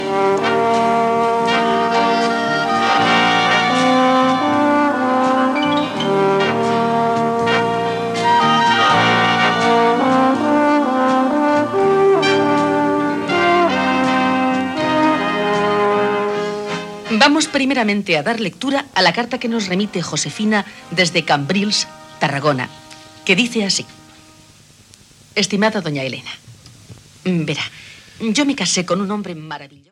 Música identificativa del programa i inici de la lectura d'una carta.